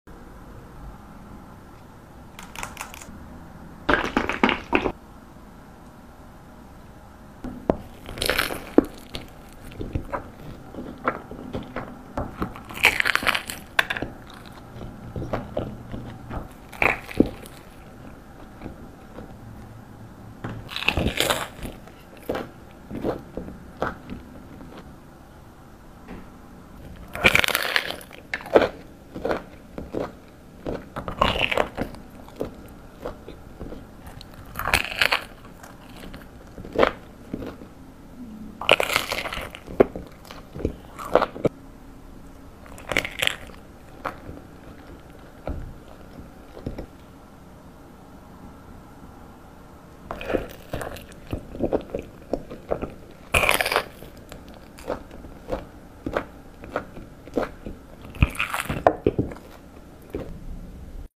Eating a crispy sound effects free download